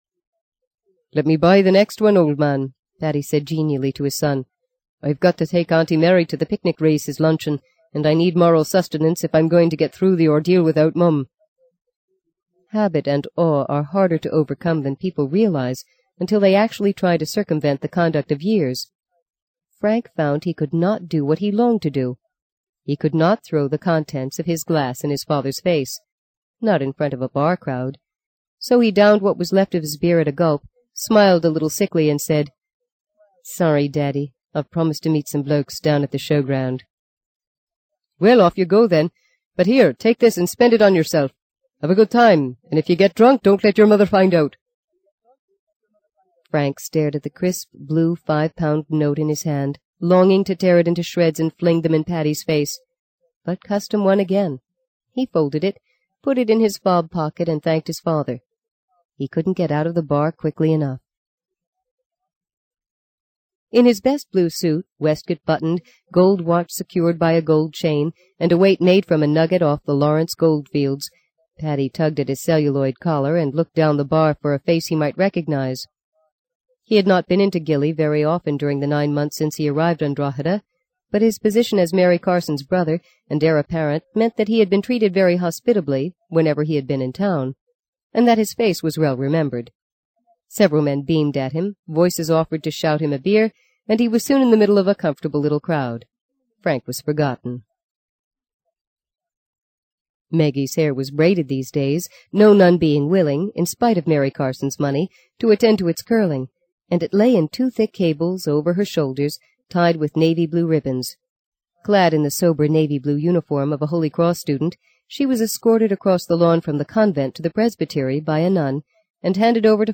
在线英语听力室【荆棘鸟】第五章 01的听力文件下载,荆棘鸟—双语有声读物—听力教程—英语听力—在线英语听力室